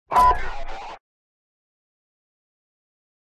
Download Squadmate Jump sound effect for free.